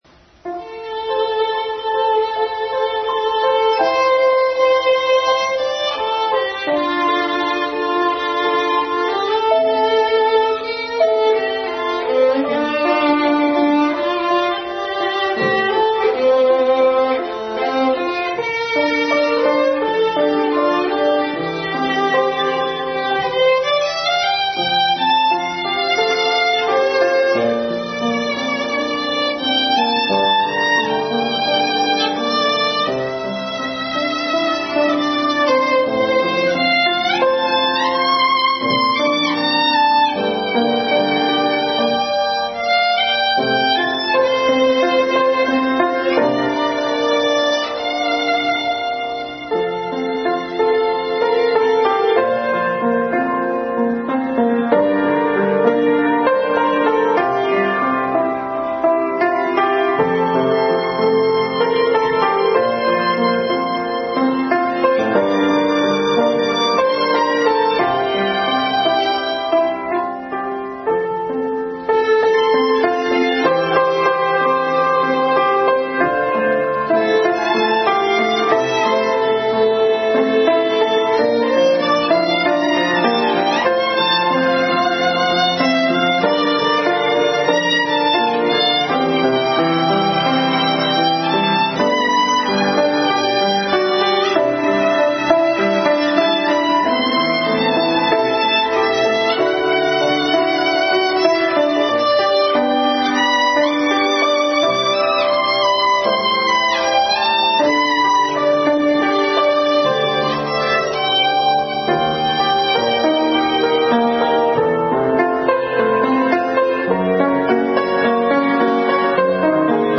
| Violin and Piano duet.
February 24, 2019 Special Music – 2/24/2019 – “Here Am I Lord Send Me” Service Type: Family Bible Hour | Violin and Piano duet.